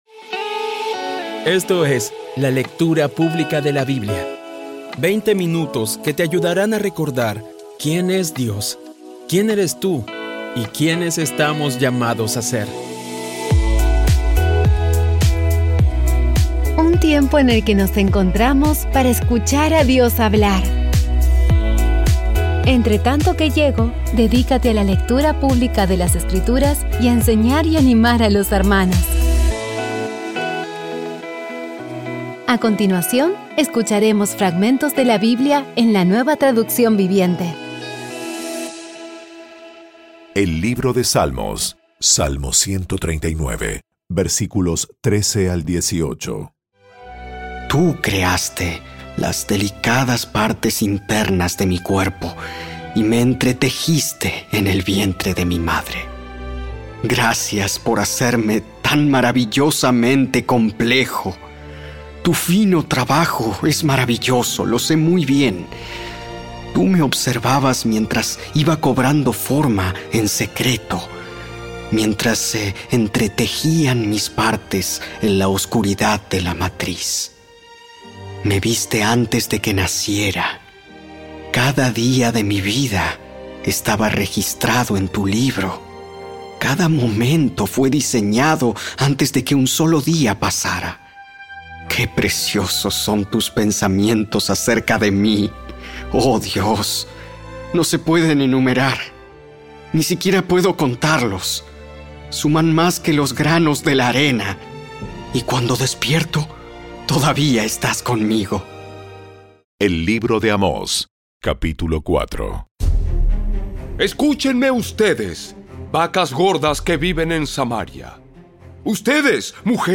Audio Biblia Dramatizada Episodio 345
Poco a poco y con las maravillosas voces actuadas de los protagonistas vas degustando las palabras de esa guía que Dios nos dio.